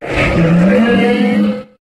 Cri de Frison dans Pokémon HOME.